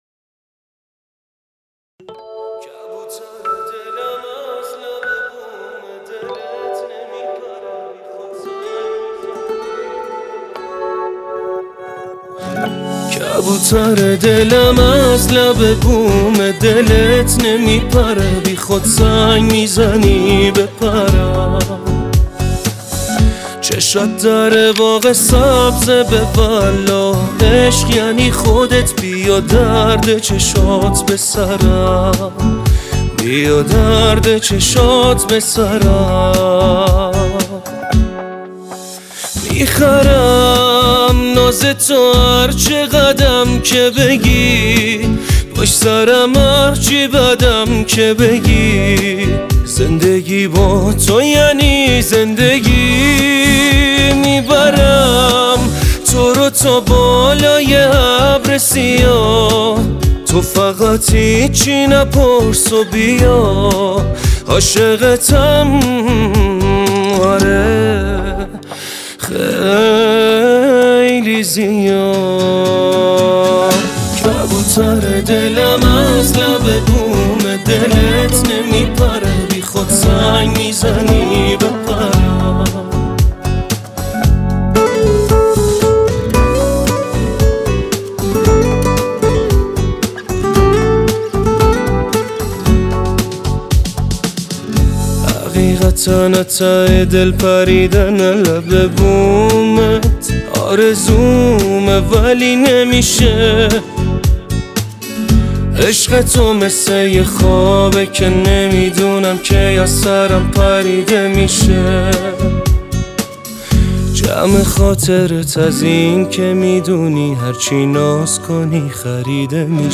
عاشقانه و دلنشین
با صدای زیبای